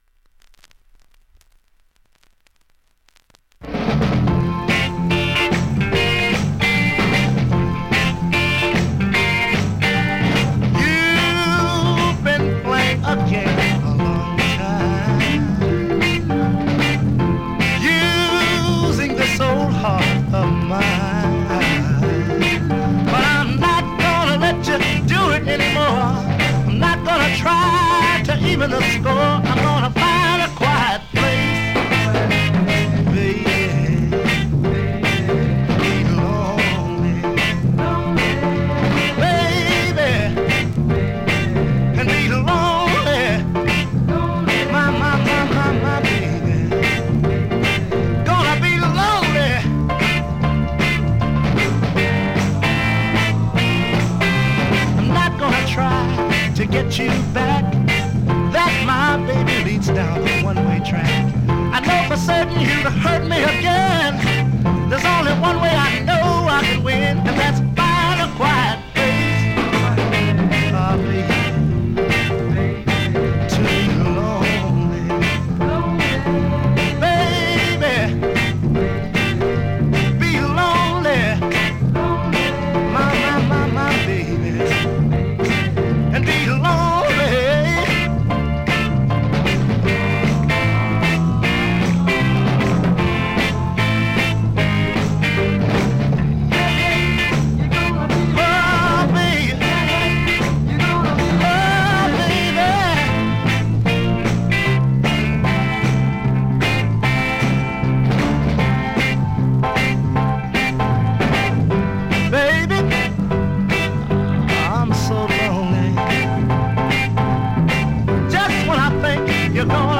現物の試聴（両面すべて録音時間4分43秒）できます。